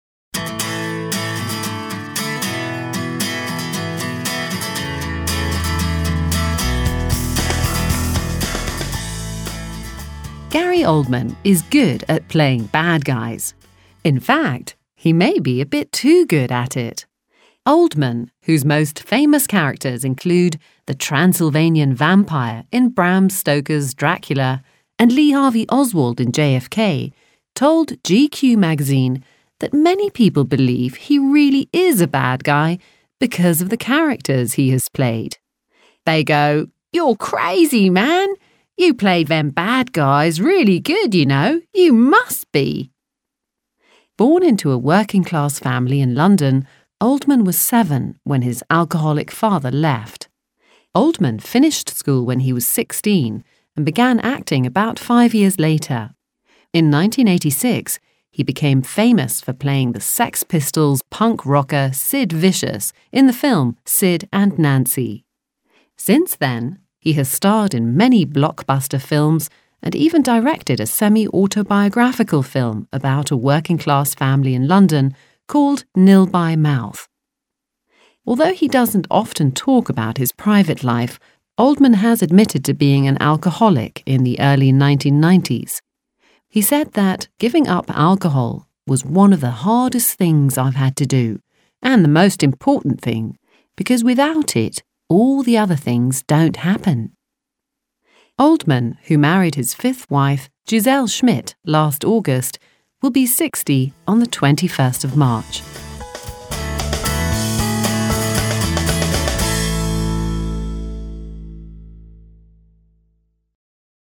Englisch lernen Audio - Vintage London - Spotlight Verlag - Hörbuch